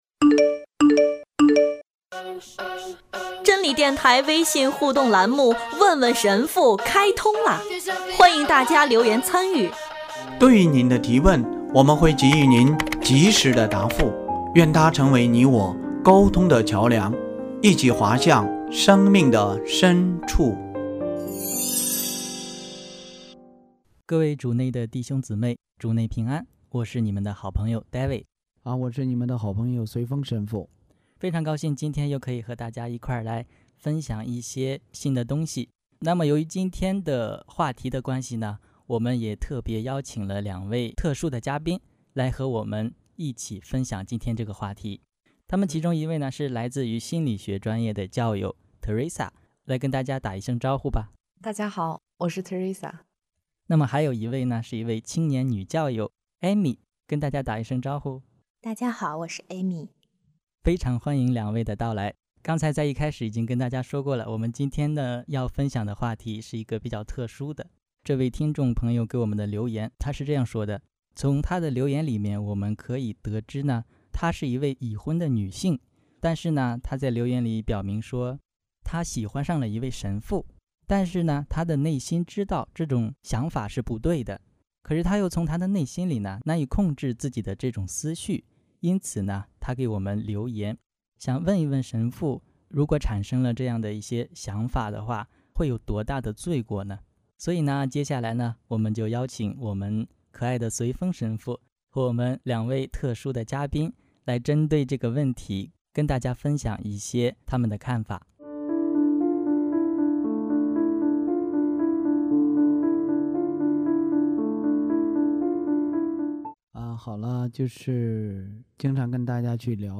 今天我们特别邀请了两位嘉宾，一起来为我们分享这个问题。